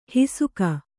♪ hisuka